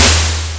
Explosion
ab07_explosion.wav